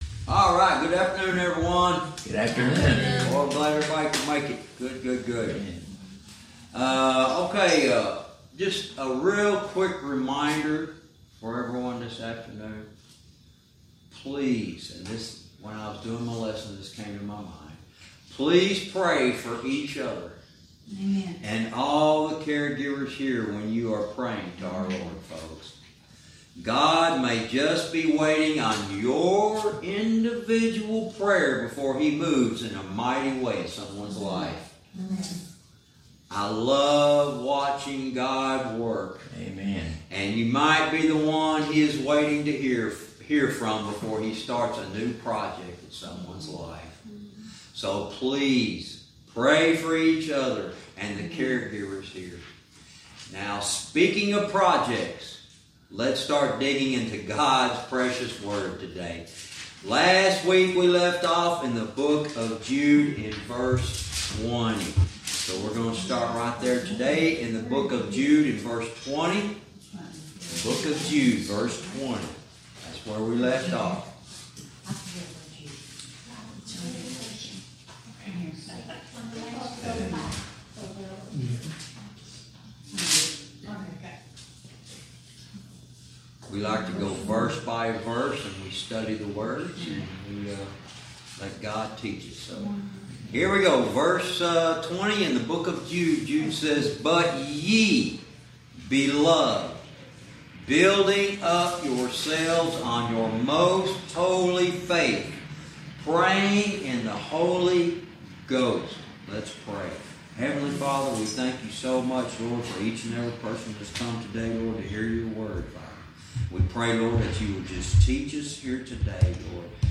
Verse by verse teaching - Jude lesson 89 verse 20